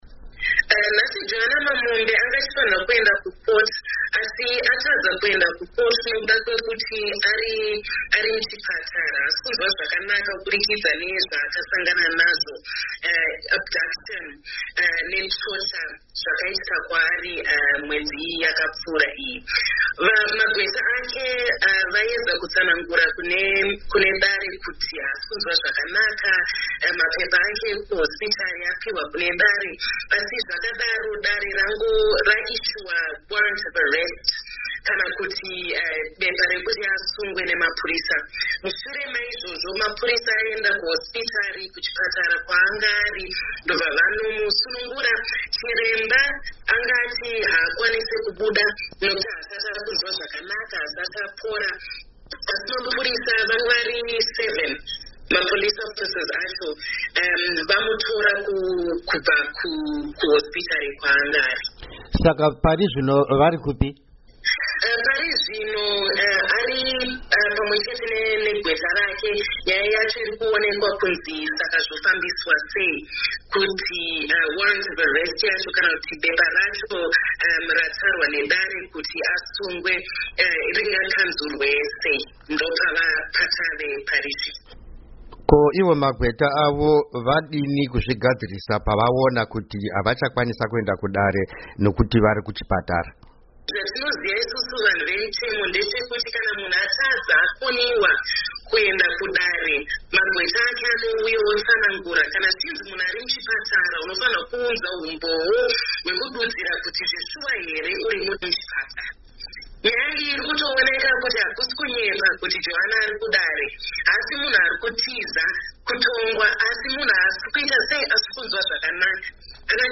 Hurukuro naAdvocate Fadzayi Mahere